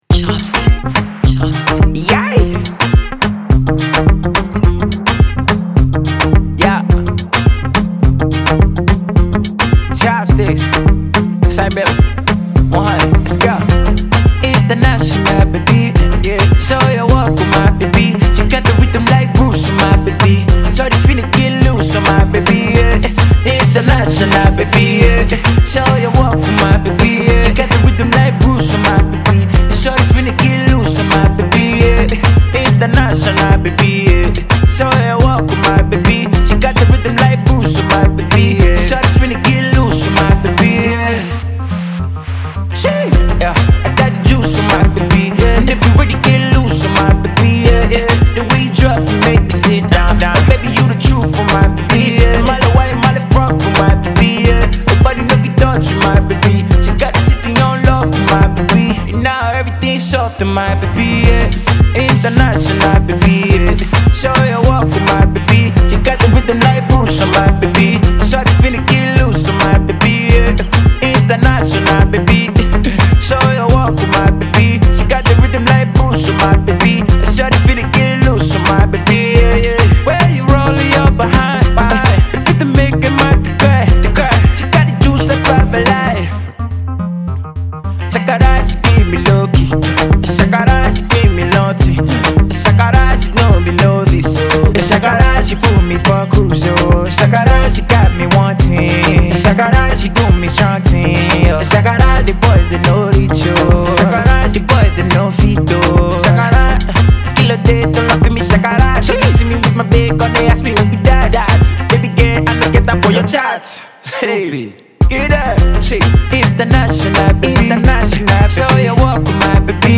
new pop sound